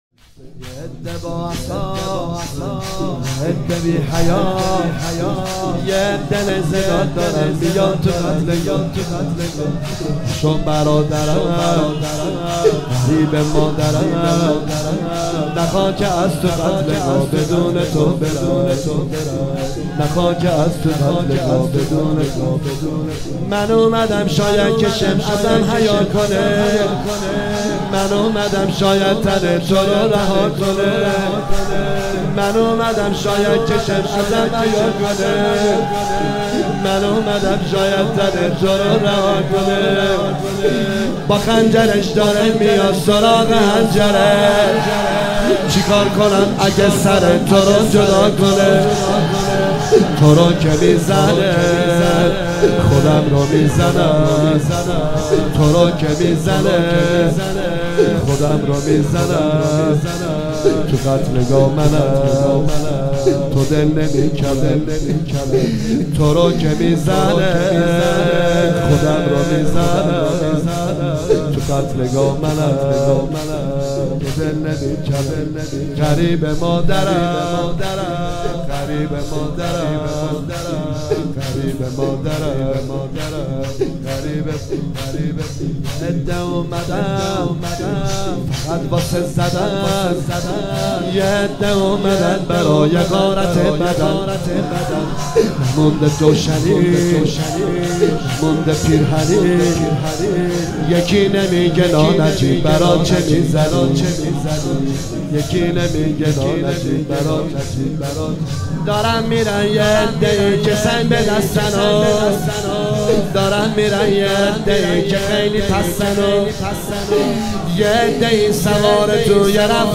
شهادت حضرت رقیه (س)